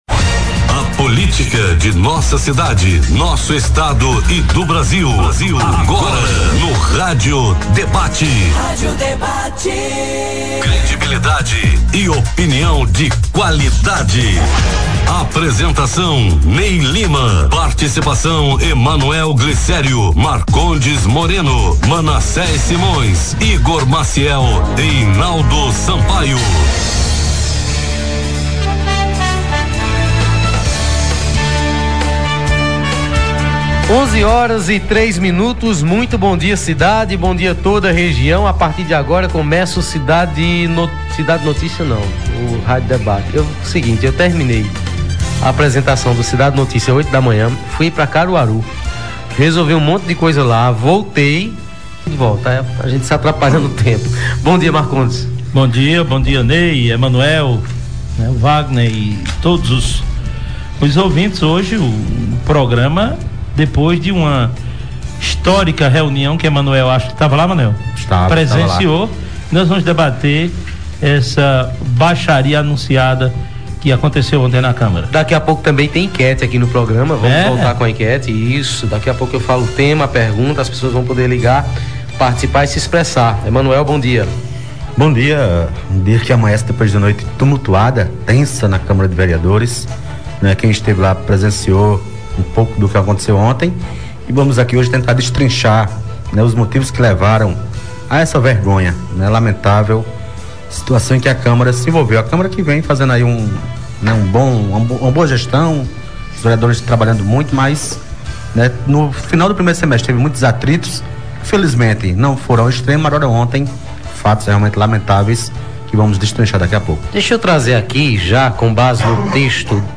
Na edição desta quarta-feira (09) do programa, veiculado pela Polo FM, os debatedores abordaram o polêmico bate-boca na câmara de vereadores entre os vereadores Deomedes Brito (PT) e Zé Minhoca (PSDB).
O programa teve as participações dos vereadores envolvidos que relataram suas opiniões.